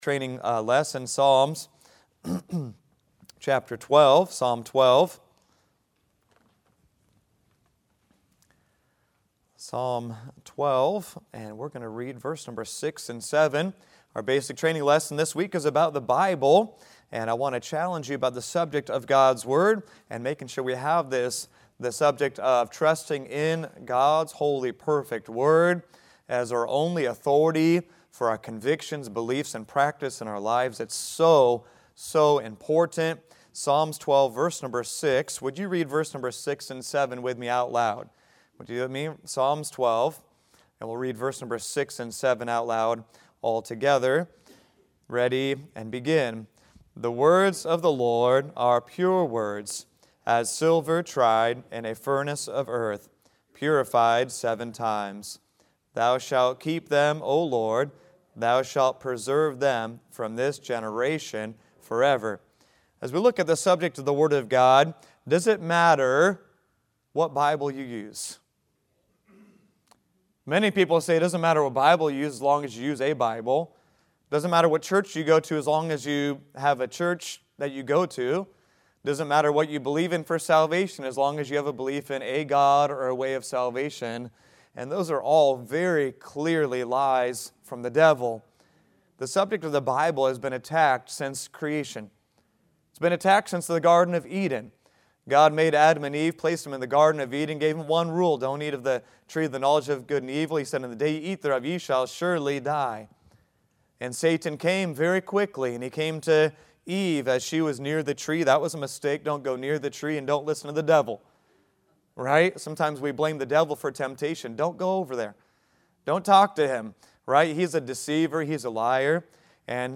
Bible | Sunday School